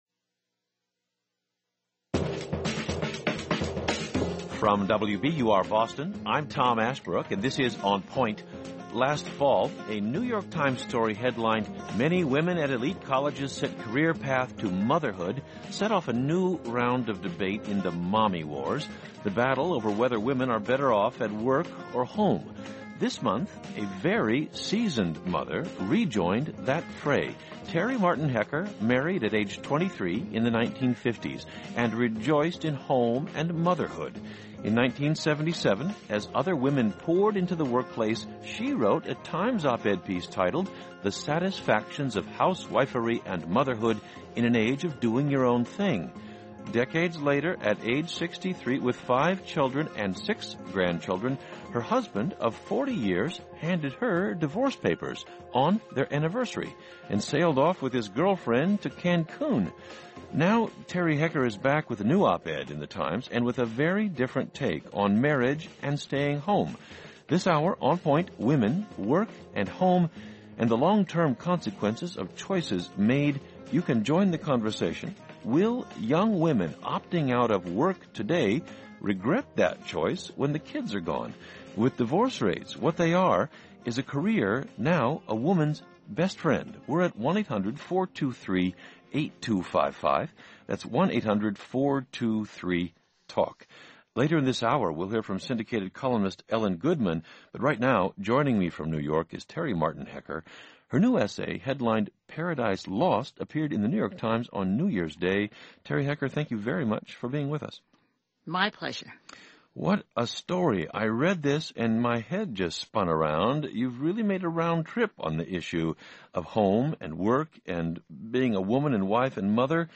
An update appeared this month titled "Paradise Lost" (Domestic Division).; Ellen Goodman, syndicated columnist for The Boston Globe.